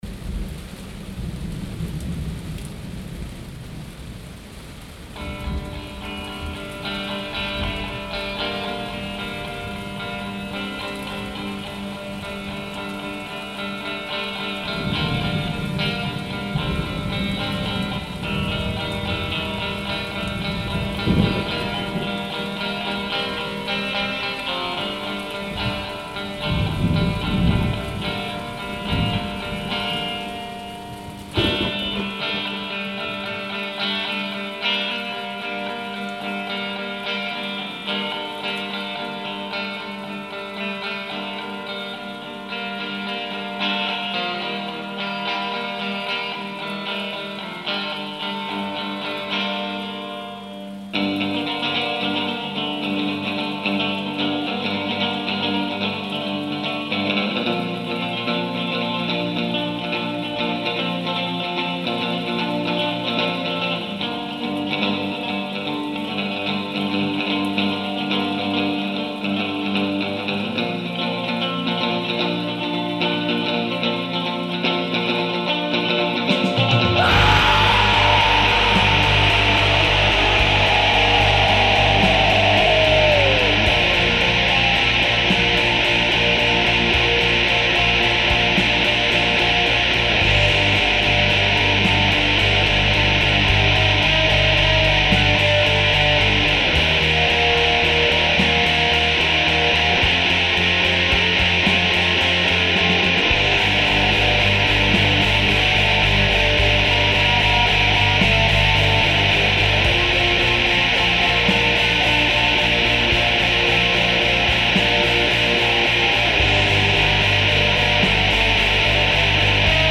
*фонова композиція